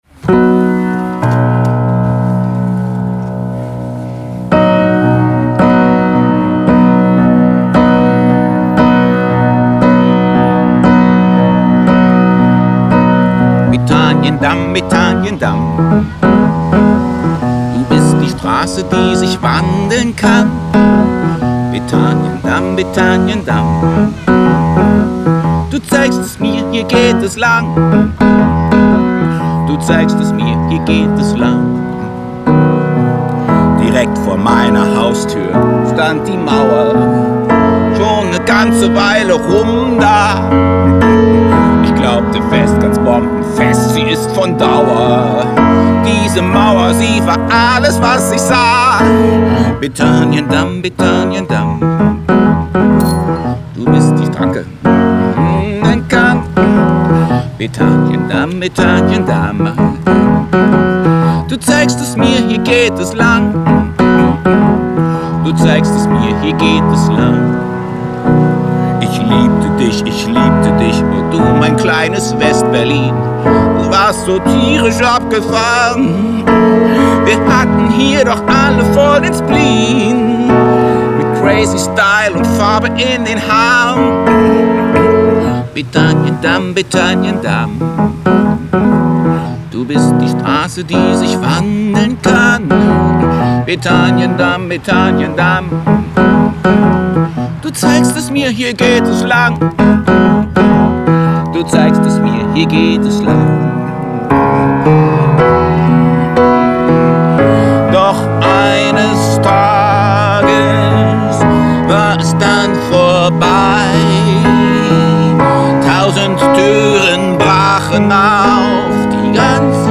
Waldpflanzengarten: